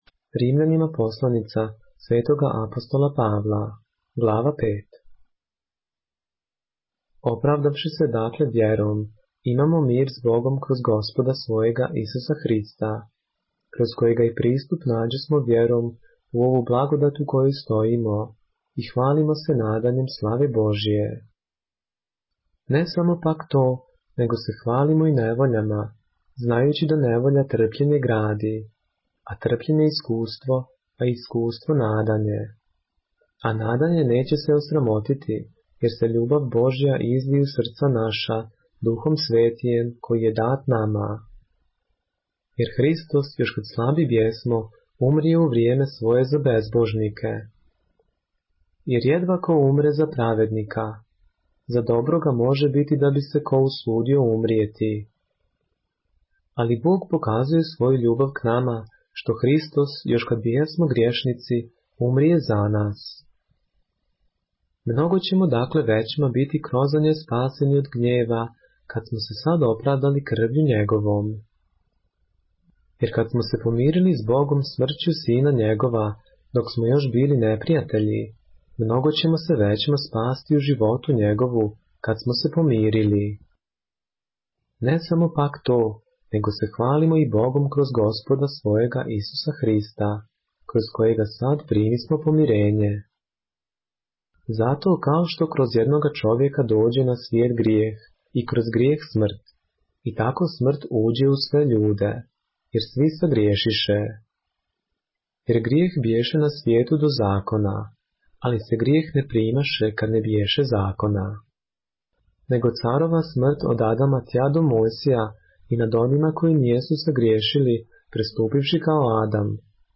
поглавље српске Библије - са аудио нарације - Romans, chapter 5 of the Holy Bible in the Serbian language